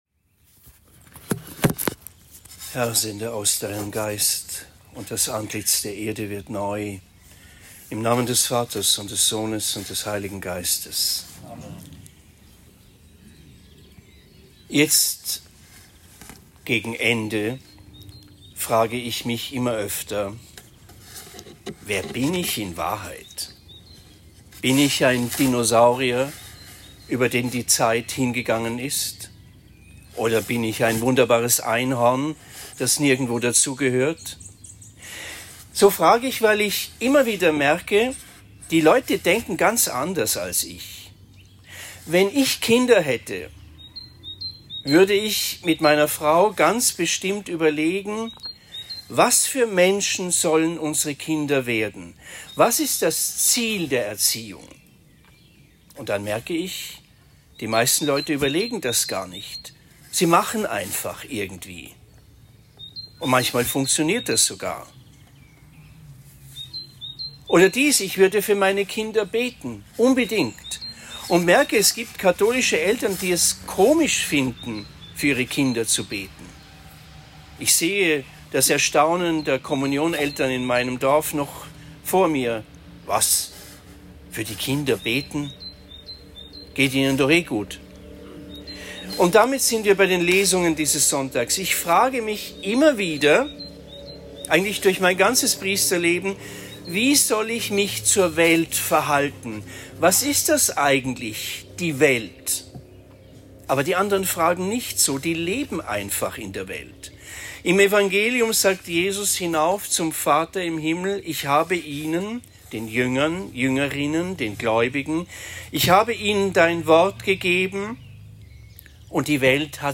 Siebter Sonntag der Osterzeit : Flurprozession, Hl. Messe unter den Bäumen am Main
Sakramentsprozession in Trennfeld am 12. Mai 2024